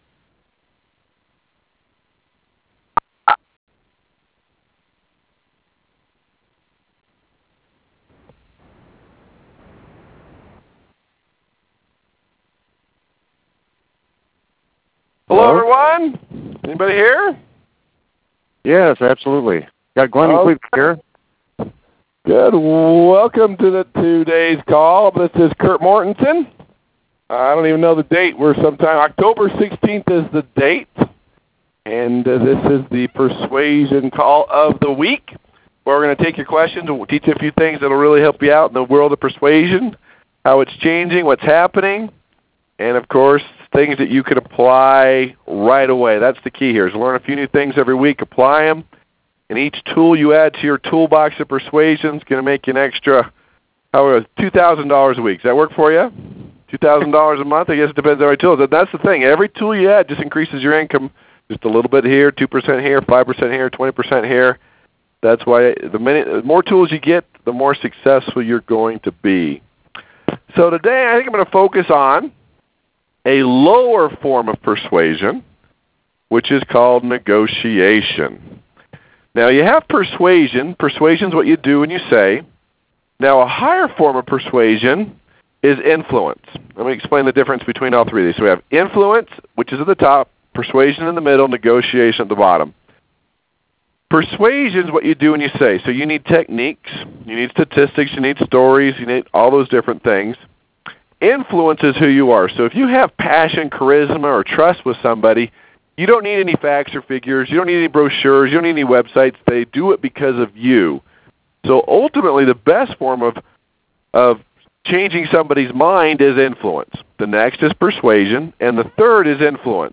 ‹ Negotiation #6 Emotions 101 Negotiation and Blunders › Posted in Conference Calls